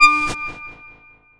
贡献 ） 分类:游戏音效 您不可以覆盖此文件。